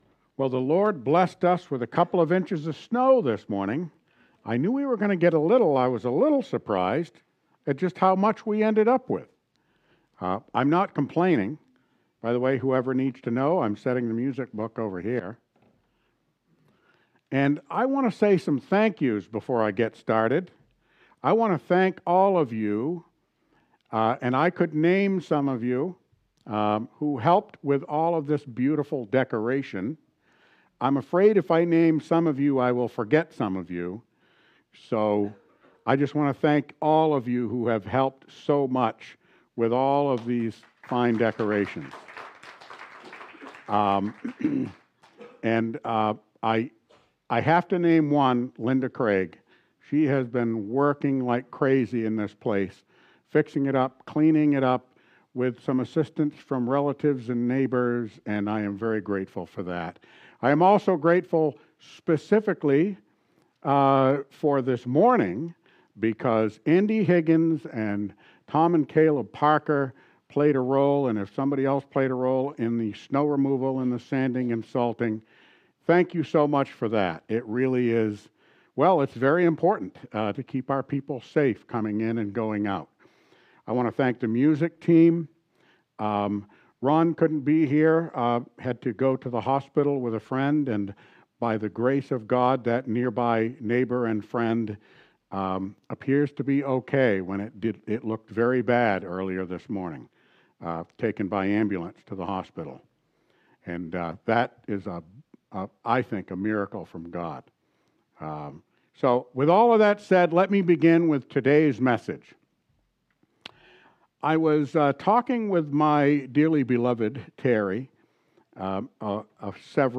Sunday, December 8, 2024, Worship Service: “The First Noel”
Sunday_December_8_2024_Worship_Service_The_First_Noel.mp3